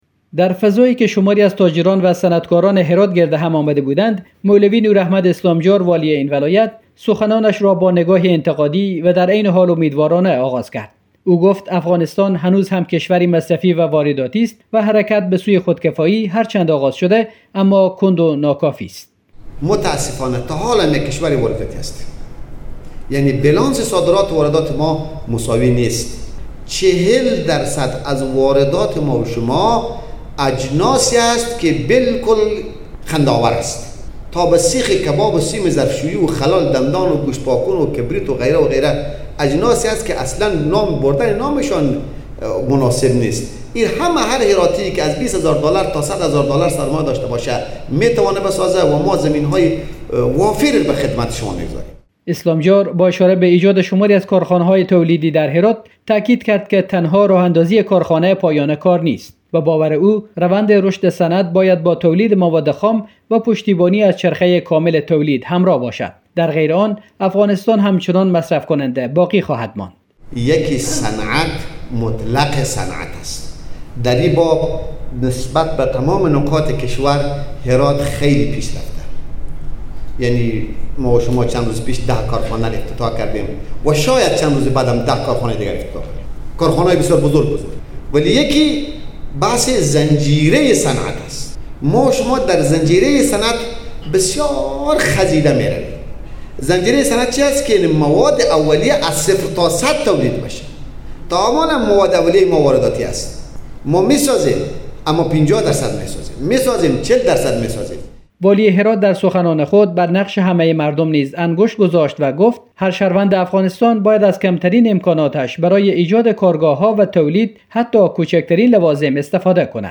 کندی صنعت و امید به خودکفایی؛ روایت والی هرات در جمع صنعتکاران